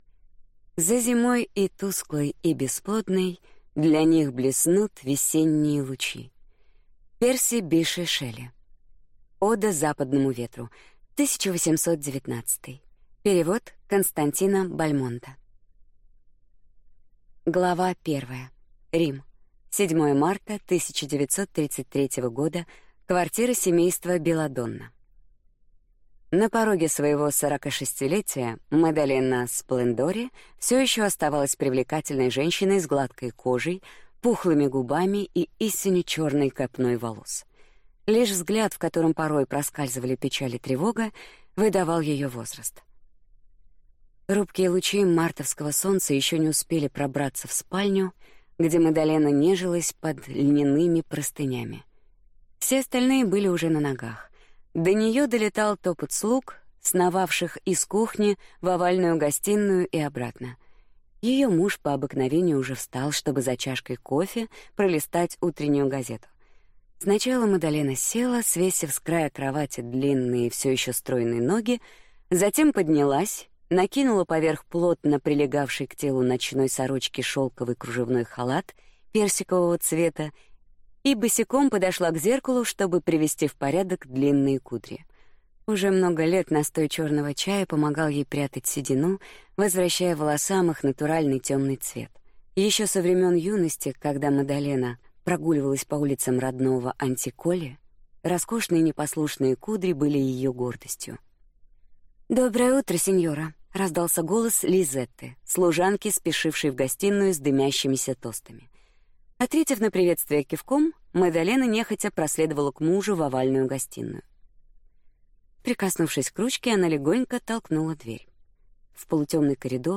Аудиокнига Пять сестер | Библиотека аудиокниг